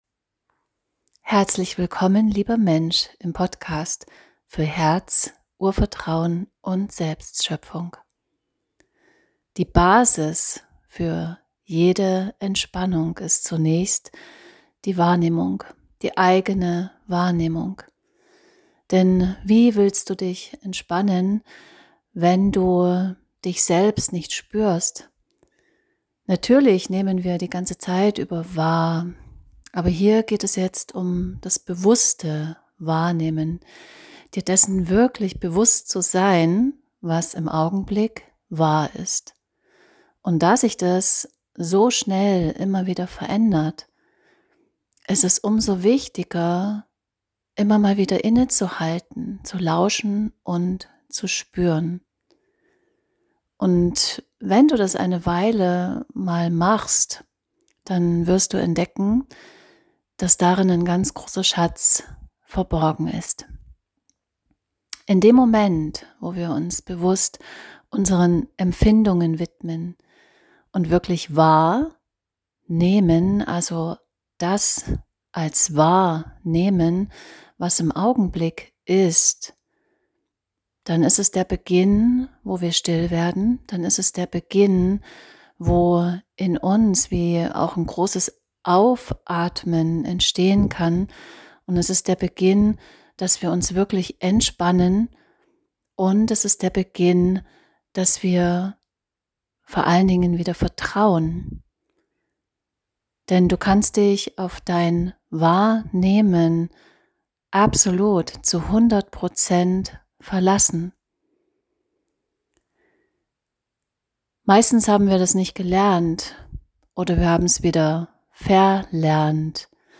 Eine einfache, kleine Meditation für überall, zur Verfeinerung deiner Sinne. Deine Sinne sind der Schlüssel zur Wahrnehmung und zu deiner Intuition.